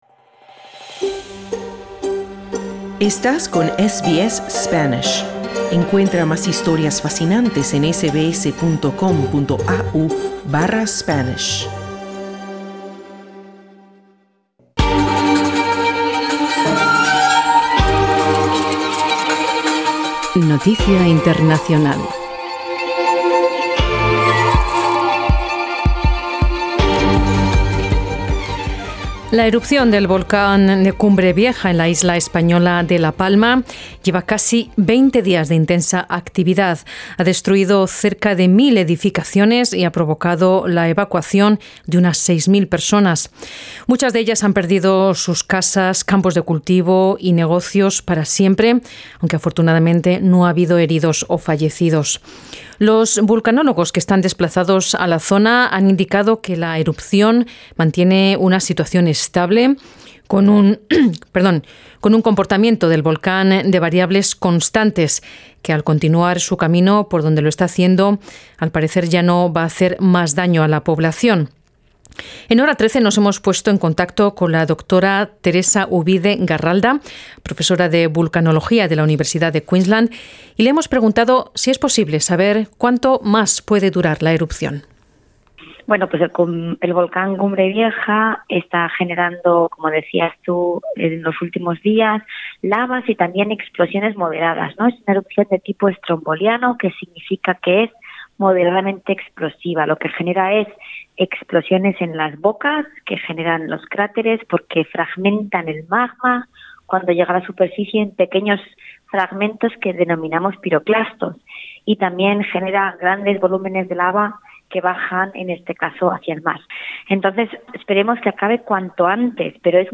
La erupción del volcán de Cumbre Vieja en la isla española de La Palma lleva cerca de 20 días de intensa actividad, ha destruido alrededor de 1,000 edificaciones y ha provocado la evacuación de unas 6,000 personas. La concejala de seguridad de uno de los pueblos afectados explica las dificultades de los vecinos para acceder a sus campos de cultivo, mientras que los vulcanólogos indican que la erupción puede durar semanas o meses.